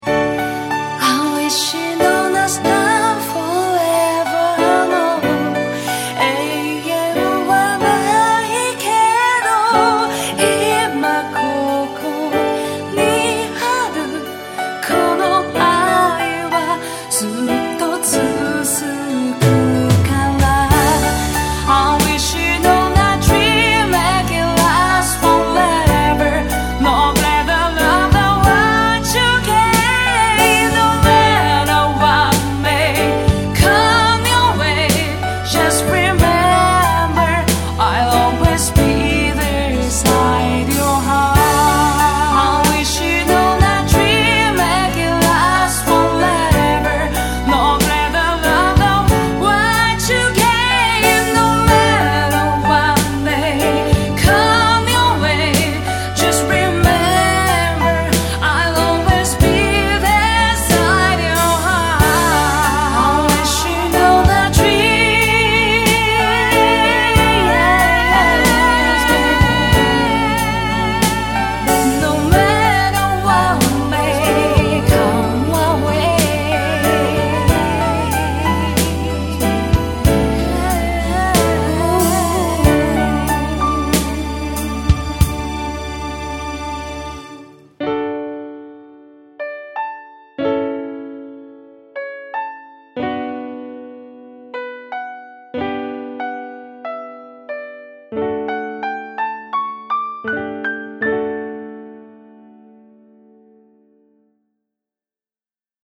梅雨の蒸し蒸しした中、相方さんは洗○所で歌を歌う。
そんな状況からできたオリジナル曲です。